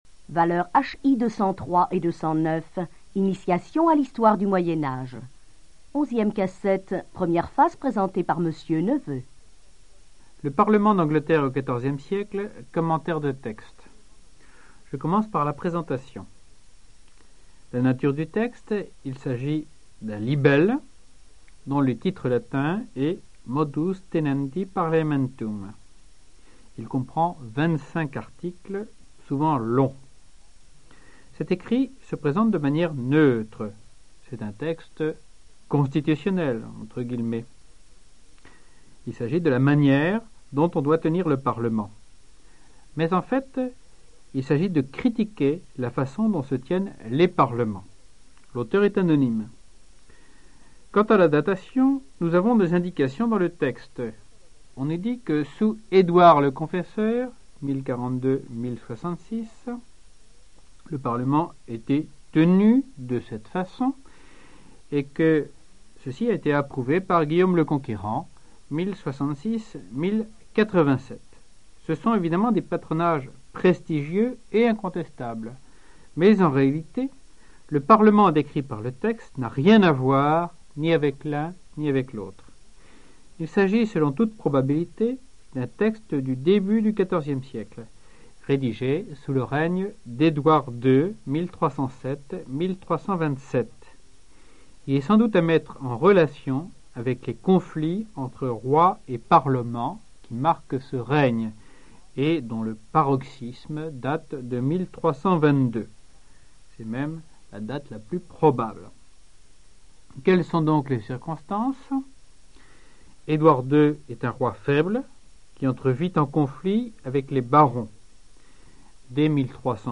Archives cours audio 1987-1988